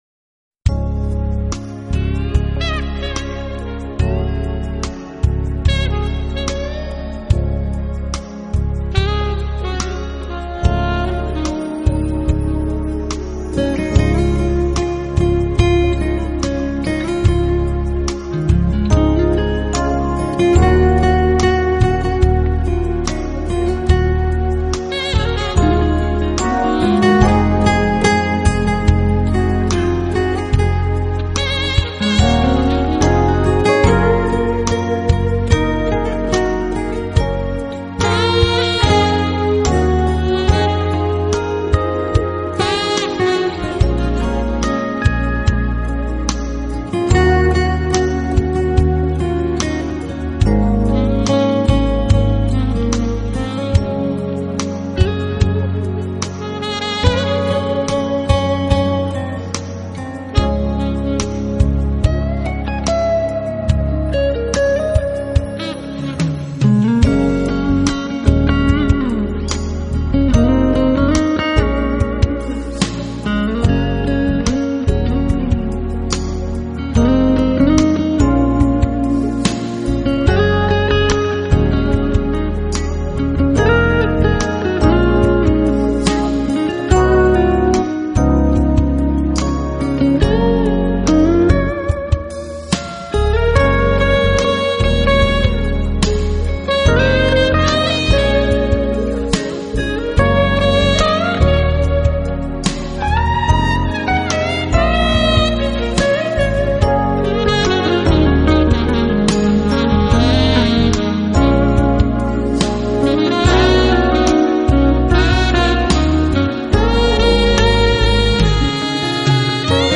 音乐类型：Jazz